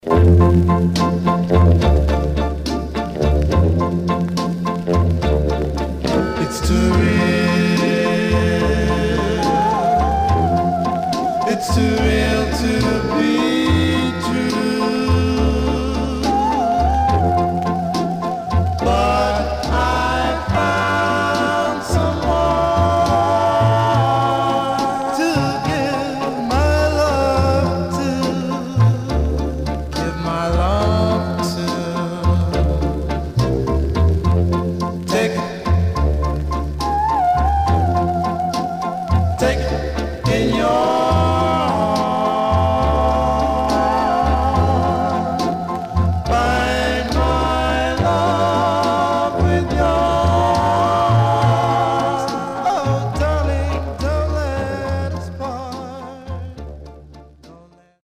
Surface noise/wear Stereo/mono Mono
Male Black Groups